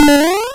BigJump.wav